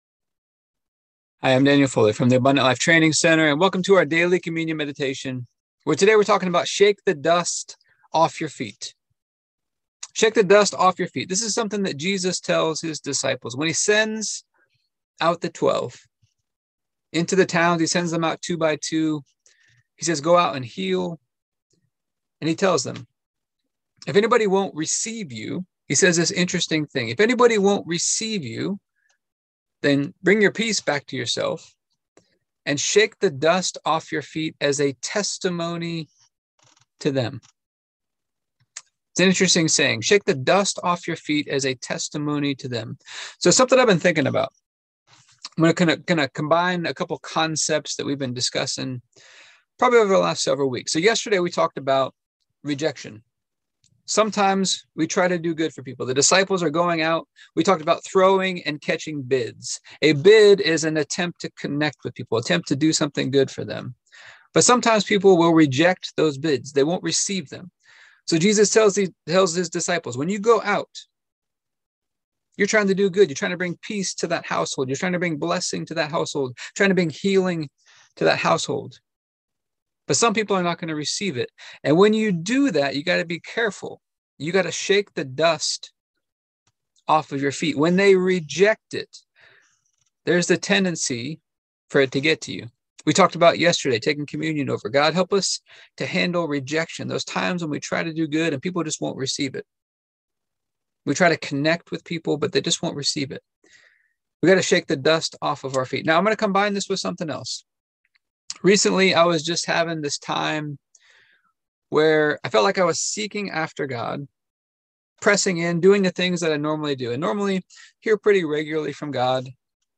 Here is today's communion meditation.